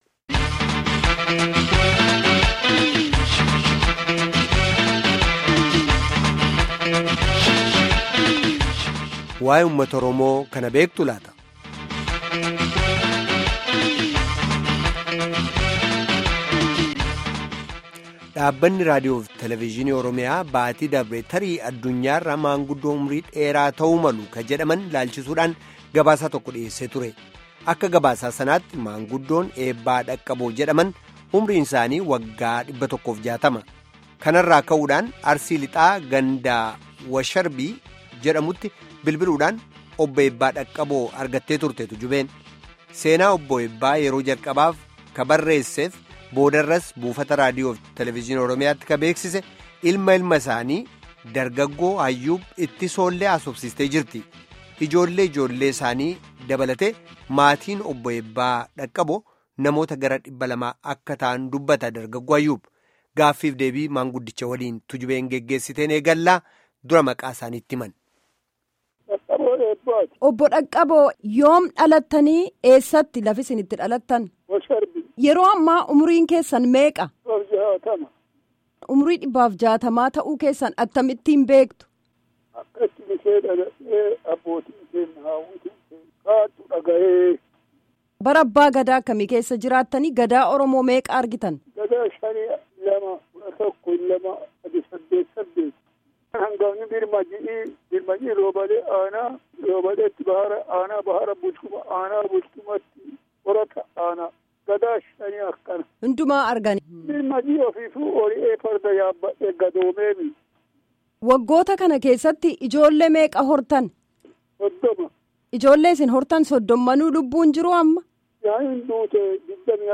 Guutummaa gaaffii fi deebii kanaa dhaggeeffadhaa